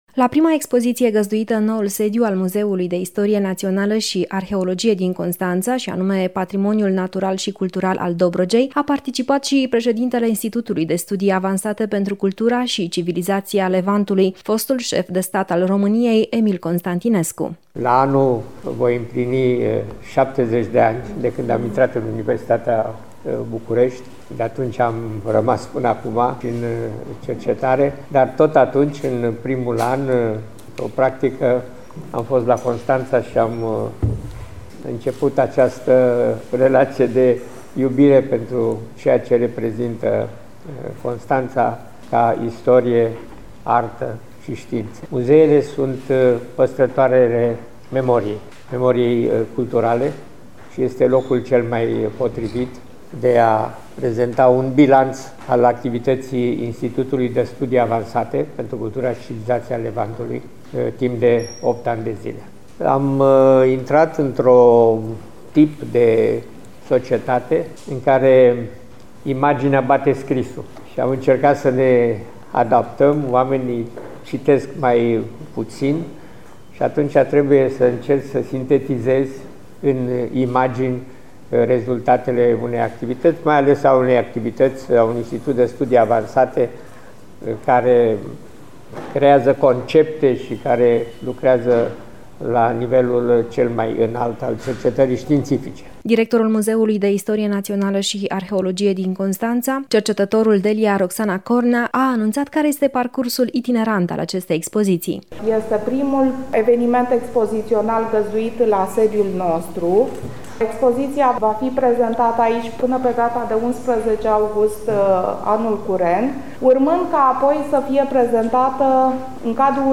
Eveniment cultural  emoționant, astăzi, la noul sediu al Muzeului de Istorie Națională și Arheologie din Constanța.
Acesta a povestit istoricilor și jurnaliștilor prezenți în sală despre legătura sa specială pe care o are cu orașul Constanța și despre rolul esențial al muzeelor, acela de a fi păstrătoare a memoriei culturale.